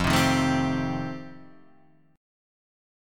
F9 chord